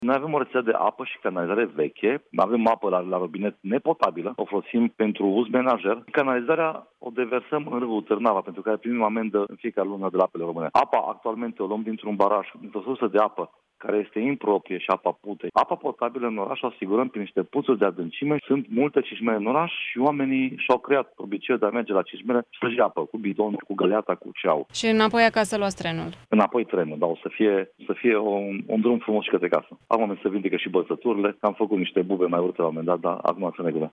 Edilul Daniel Tudor Mihalache spune ca a incercat sa obtina si finantari europene pentru a rezolva problema apei potabile in Copsa Mica, dar acel proiect neonorat de Ministerul Mediului a dus la respingerea documentatiei:
03oct-14-mihalache-despre-apa-din-oras-.mp3